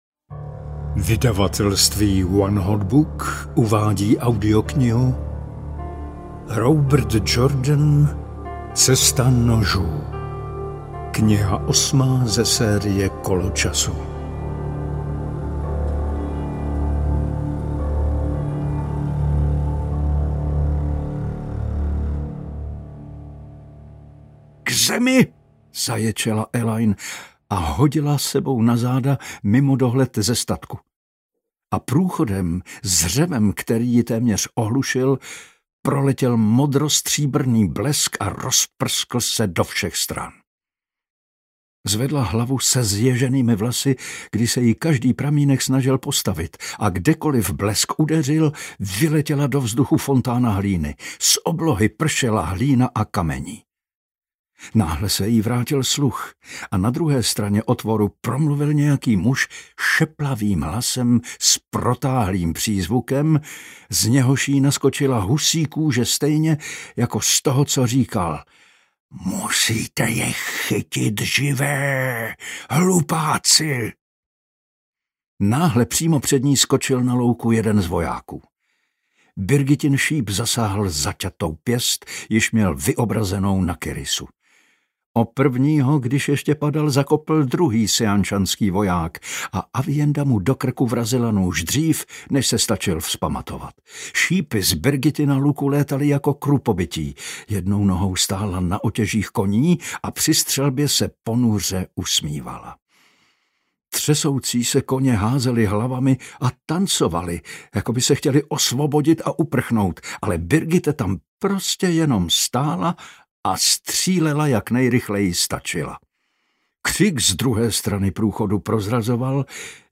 Cesta nožů audiokniha
Ukázka z knihy